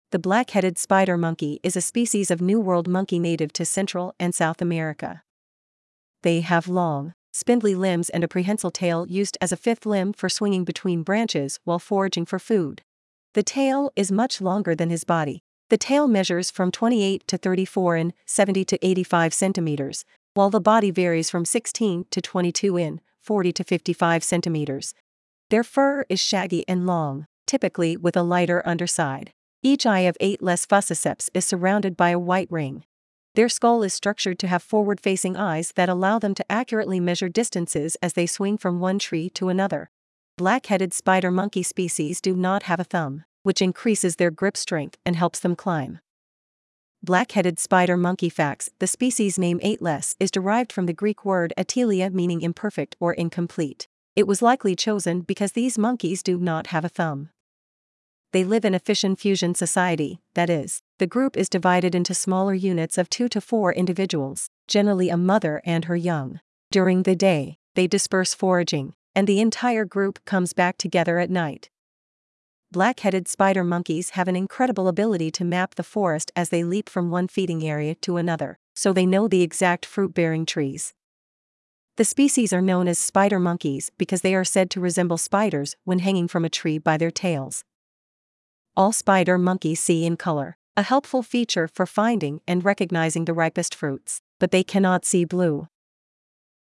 Black-Headed Spider Monkey
Black-headed-spider-monkey.mp3